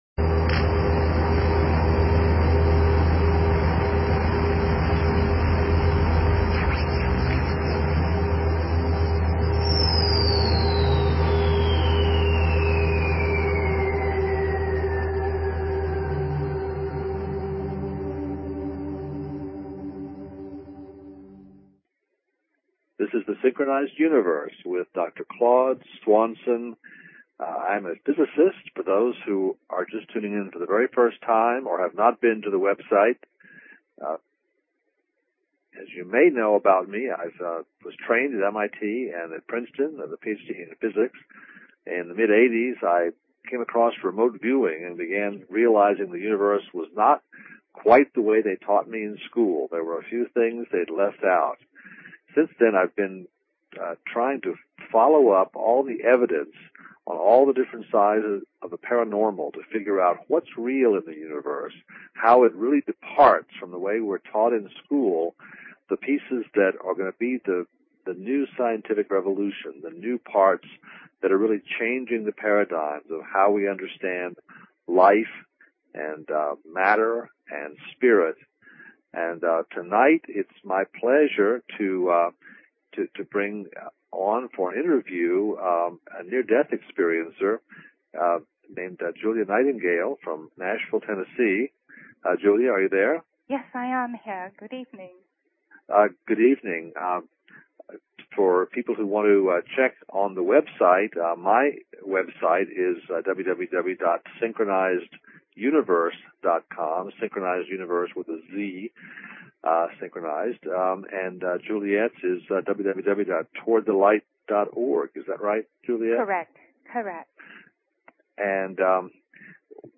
Talk Show Episode, Audio Podcast, Synchronized_Universe and Courtesy of BBS Radio on , show guests , about , categorized as
Interview